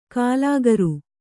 ♪ kālāgaru